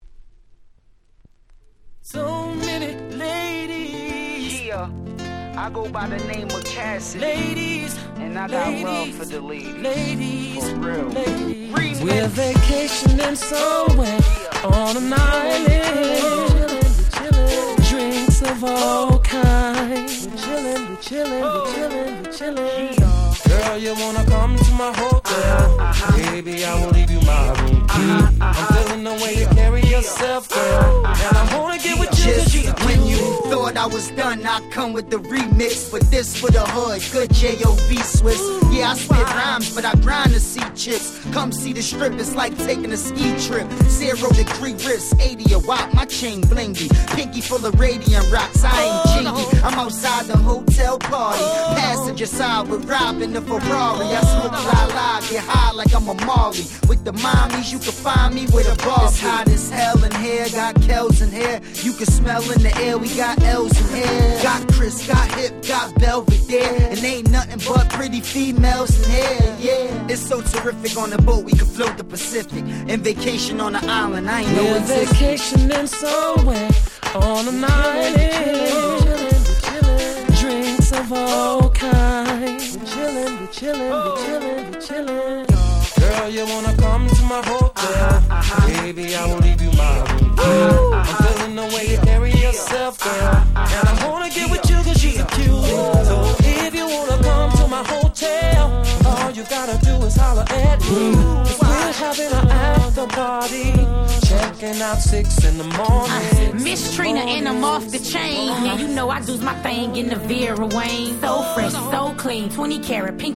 04' Super Hit Hip Hop !!